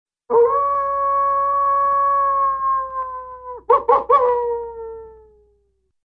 Вой волка и гавканье